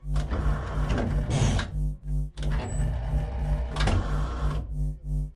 medical-assembler.ogg